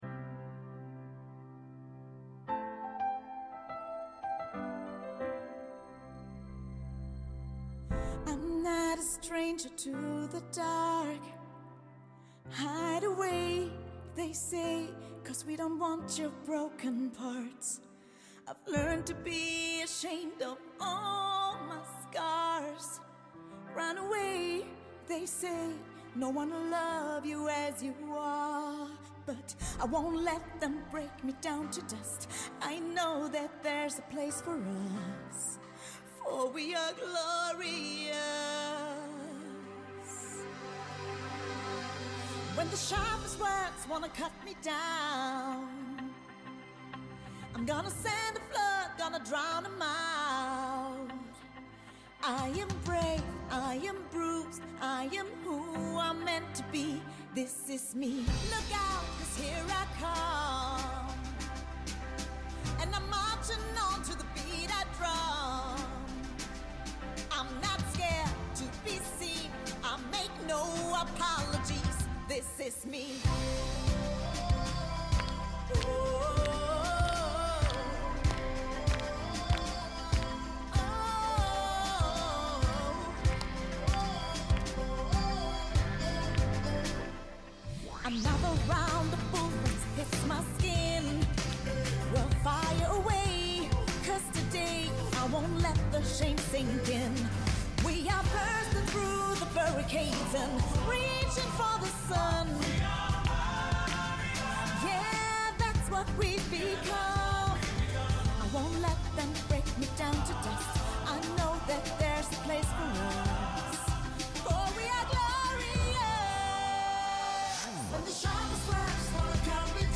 Ganzer Auftritt inkl. Bewertung.